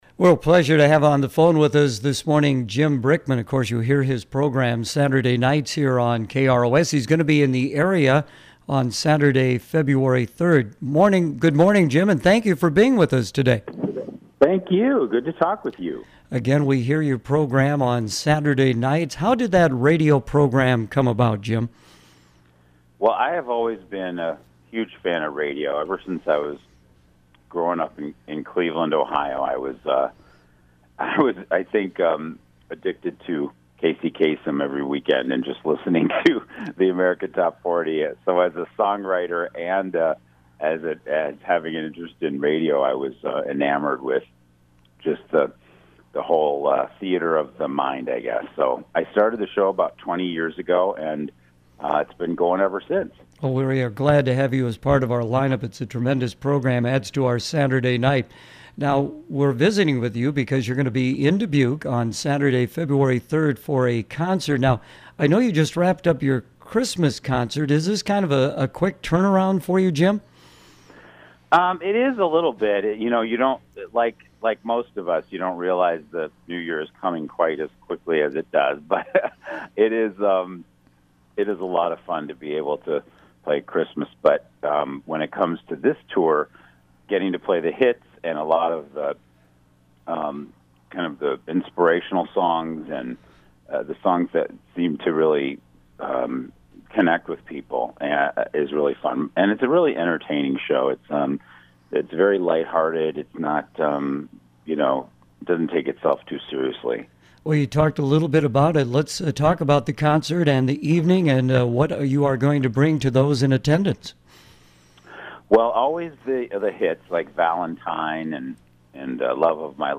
Jim Brickman Interview on KROS